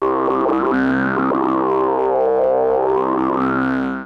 TALKING OSC 2.wav